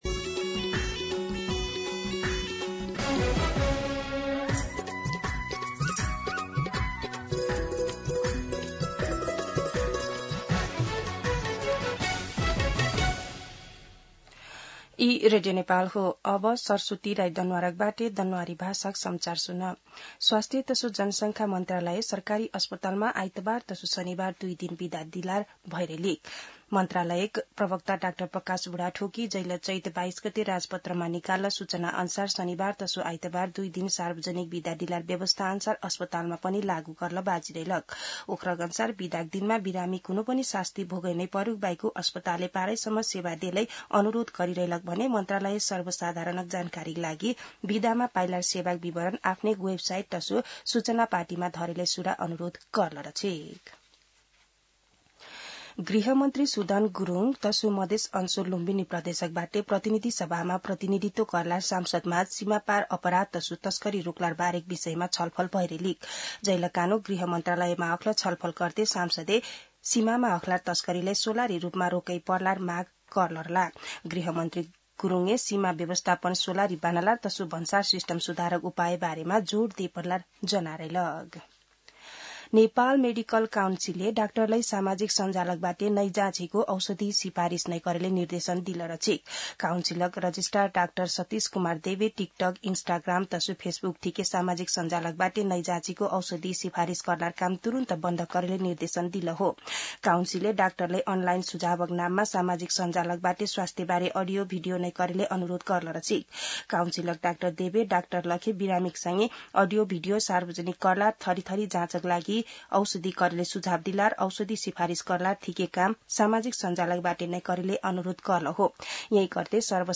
दनुवार भाषामा समाचार : ५ वैशाख , २०८३
Danuwar-News-1-5.mp3